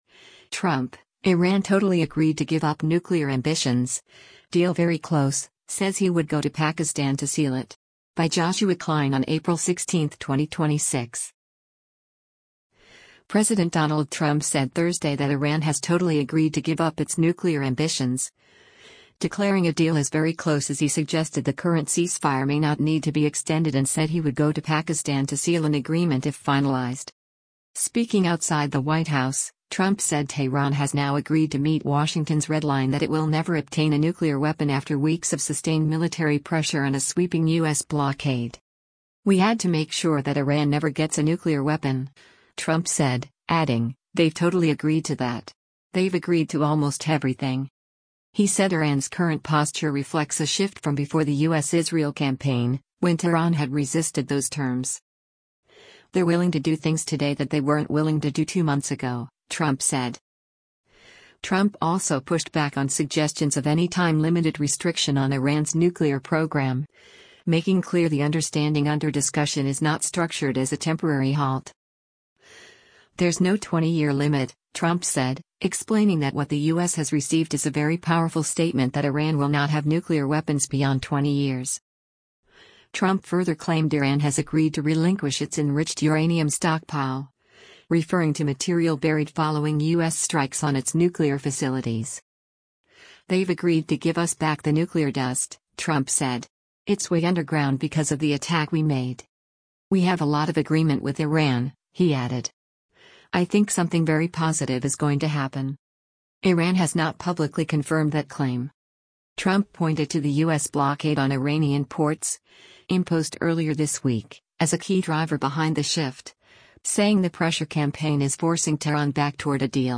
Speaking outside the White House, Trump said Tehran has now agreed to meet Washington’s red line that it will never obtain a nuclear weapon after weeks of sustained military pressure and a sweeping U.S. blockade.